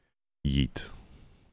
Tags: Gen Z Deep Voice Yikes